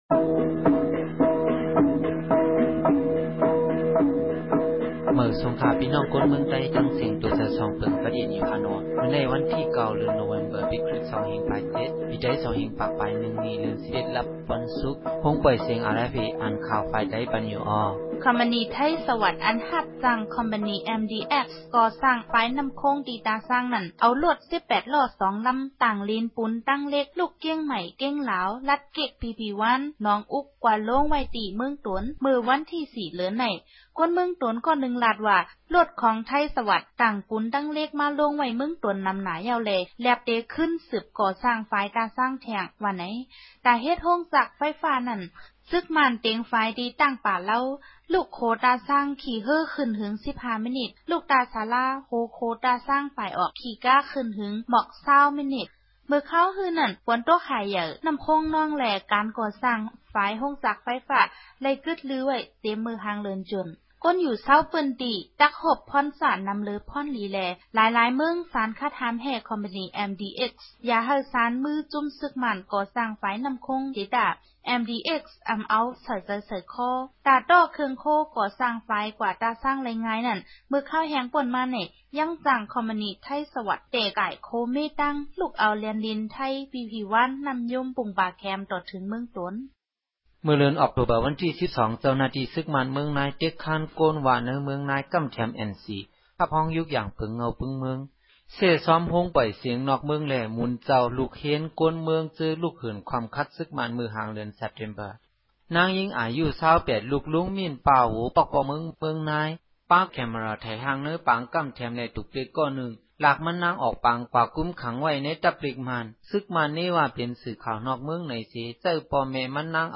ရြမ်းဘာသာ အသံလြင့်အစီအစဉ်မဵား
အပတ်စဉ် သောုကာနေႛတိုင်း ရြမ်းဘာသာူဖင့် ၅မိနစ် အသံလြင့်မည်ူဖစ်ပၝသည်။